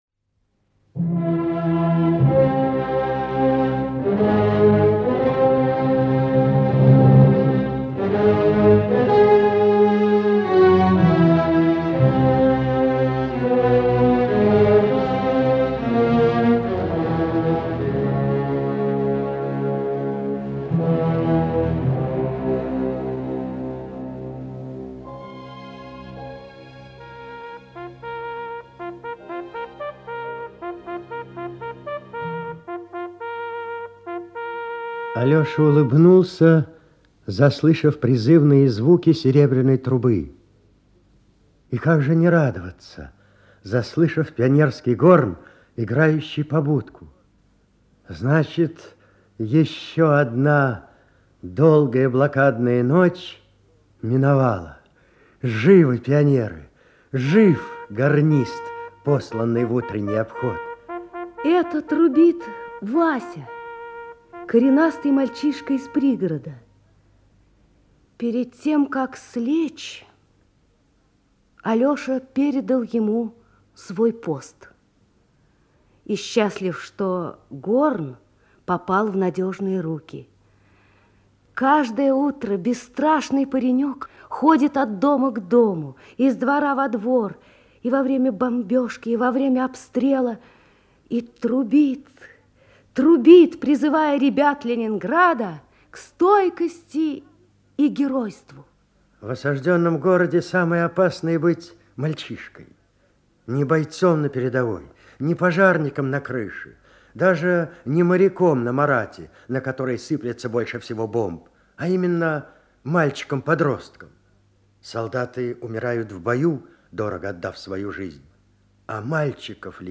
Аудио рассказ о страшной блокаде Ленинграда глазами мальчика-подростка Алёши. Алёша очень ослабел от голода.